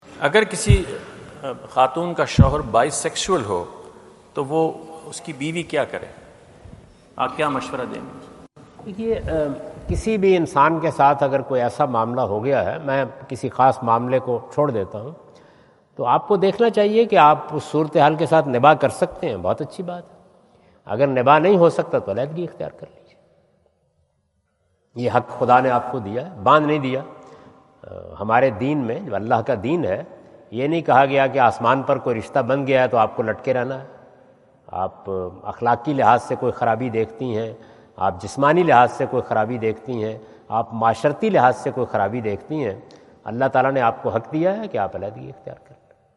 Javed Ahmad Ghamidi answer the question about "If husband is bisexual, what wife should be do?" During his US visit at Wentz Concert Hall, Chicago on September 23,2017.